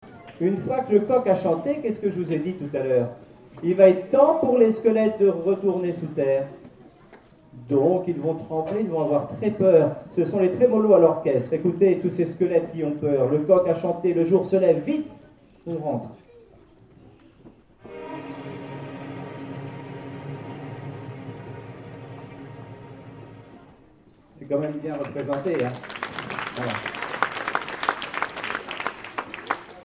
Album: Concert pédagogique 2011